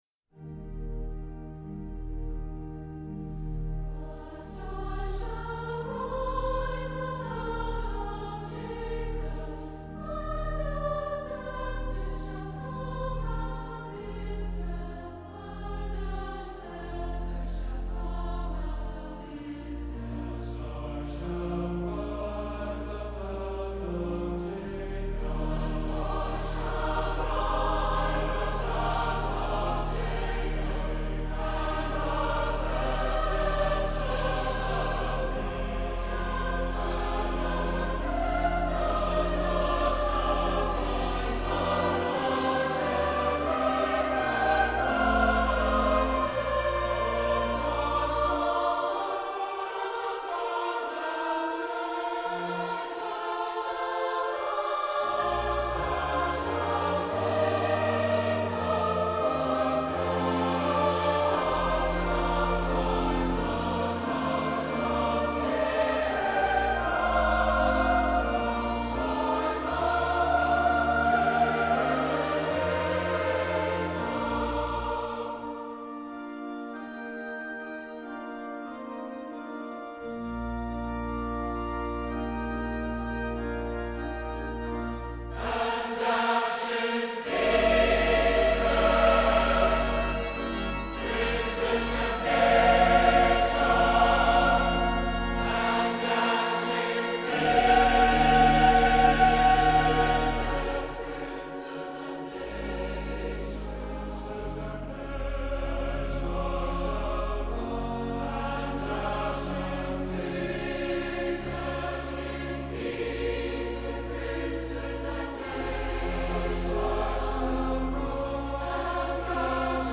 Recorded October 21, 1995 at the Provo Utah Central Stake center, the Choir for the 1995 Christmas concert numbers approximately eighty singers with most of the stake's twelve wards represented.